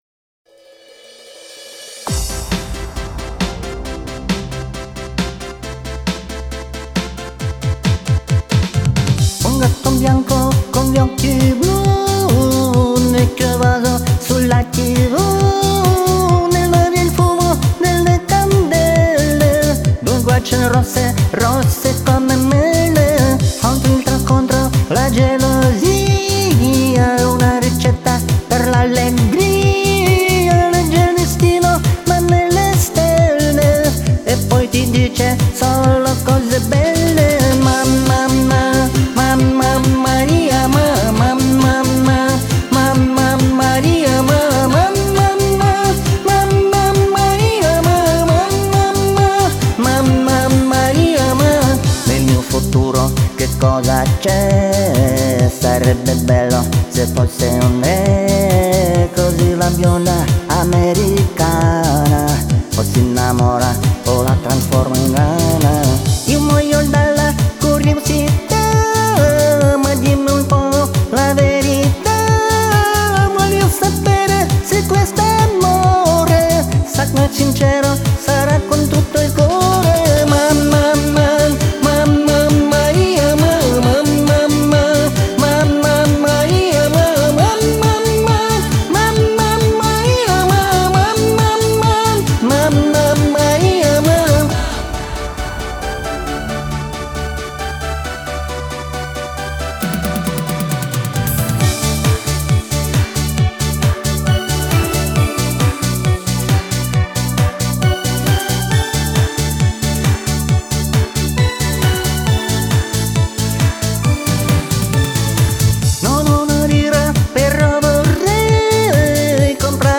любительский вокал, высокий и звонкий голос